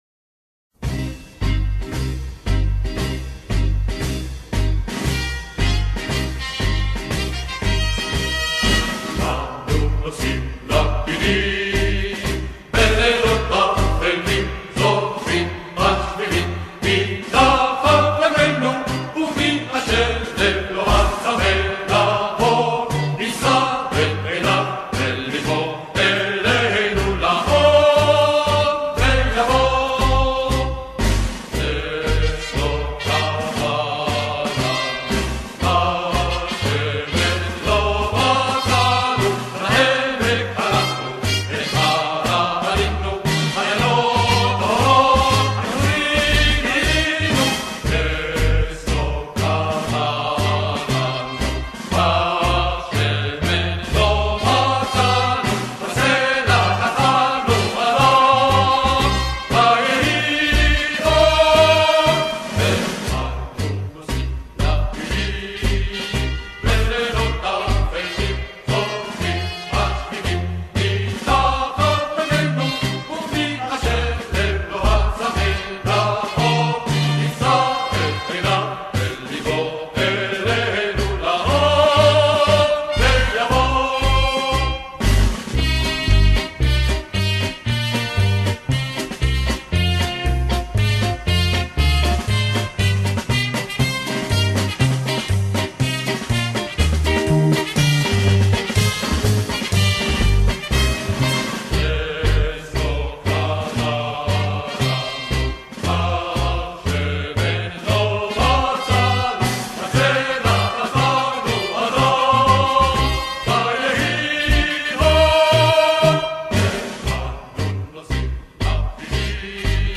פלייליסט שירי חנוכה להורדה